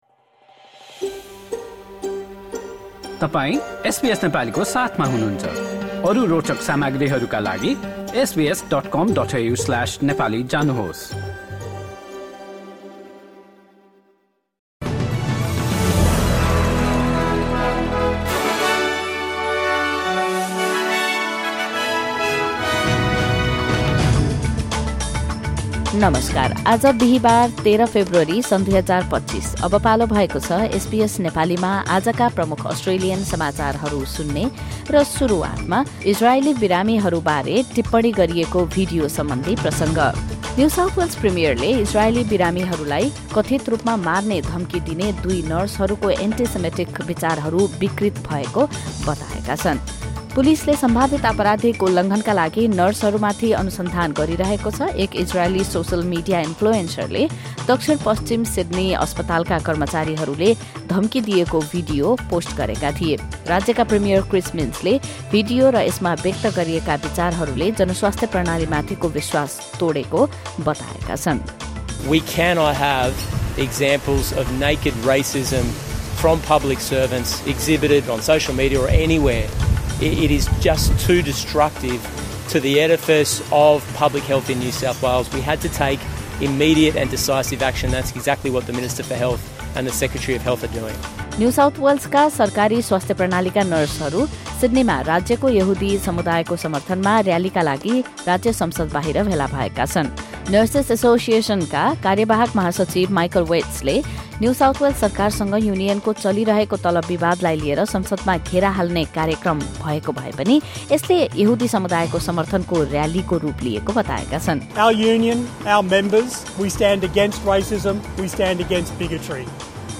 SBS Nepali Australian News Headlines: Thursday, 13 February 2025